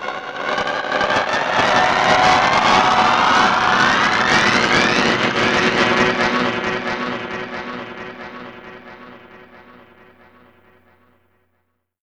Index of /90_sSampleCDs/Spectrasonics - Bizarre Guitar/Partition H/07 SCRAPE SW